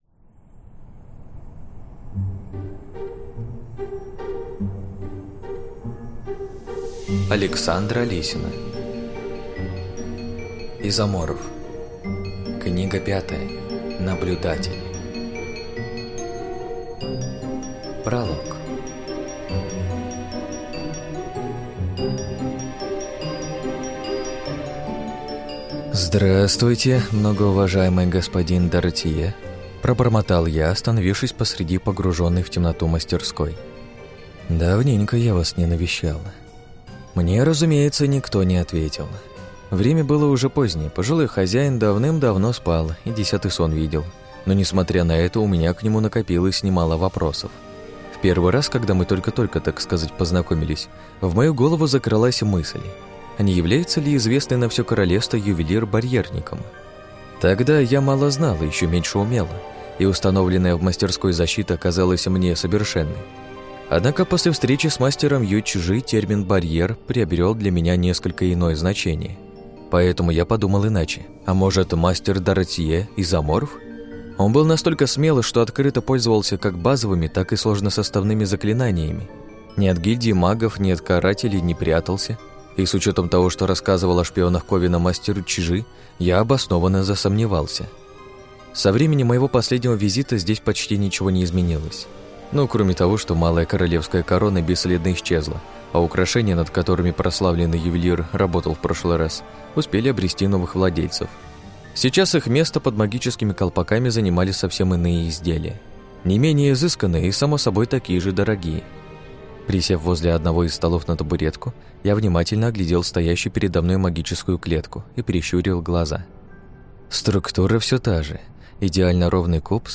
Аудиокнига Изоморф. Наблюдатель | Библиотека аудиокниг